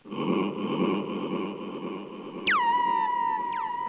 Pigs
PIGS.wav